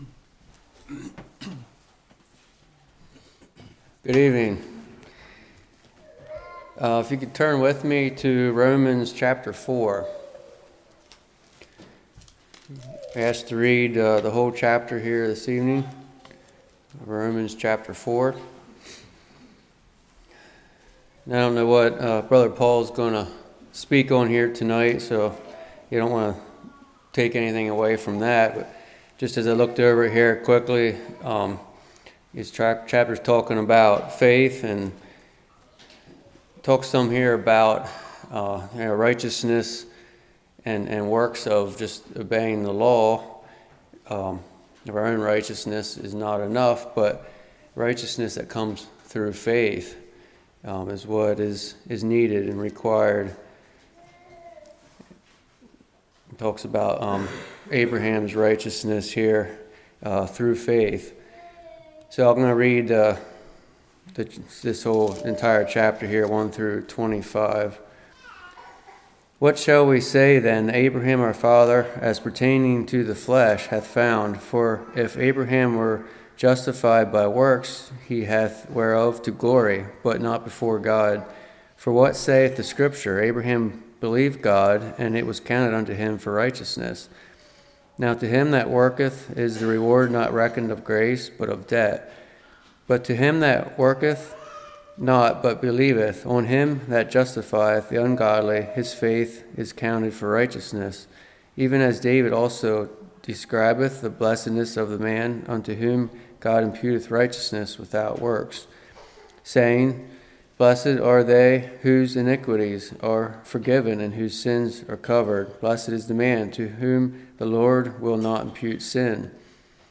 Romans 4 Service Type: Evening God uses sinners for His purposes.